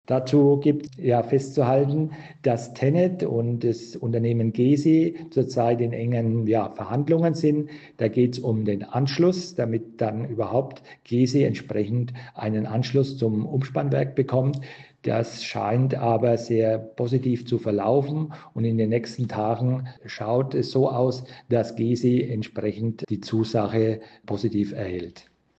Wir sprechen mit Ulrich Werner, dem Bergrheinfelder Bürgermeister.